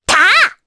Rephy-Vox_Attack3_jp.wav